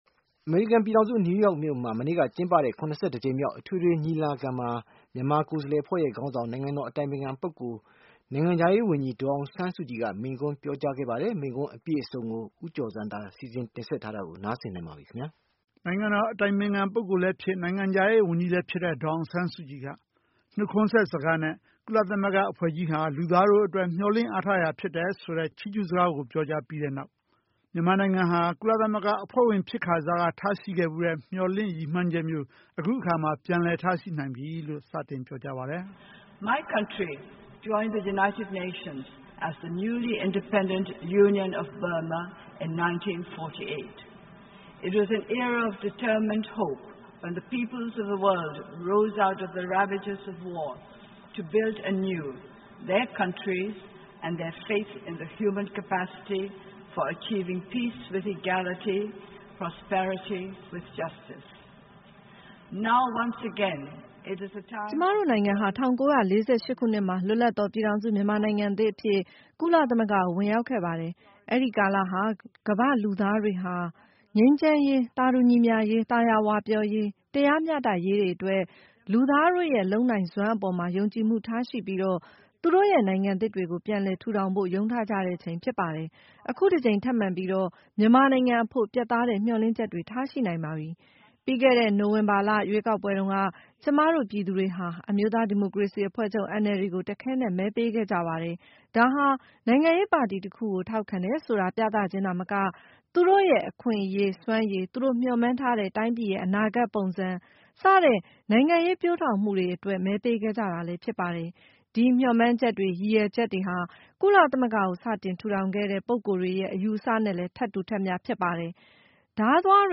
ကုလသမဂ္ဂ အထွေထွေညီလာခံမှာ ပြောကြားတဲ့ ဒေါ်စု မိန့်ခွန်း အပြည့်အစုံ